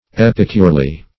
epicurely - definition of epicurely - synonyms, pronunciation, spelling from Free Dictionary Search Result for " epicurely" : The Collaborative International Dictionary of English v.0.48: Epicurely \Ep"i*cure`ly\, adv.
epicurely.mp3